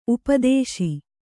♪ upadēśi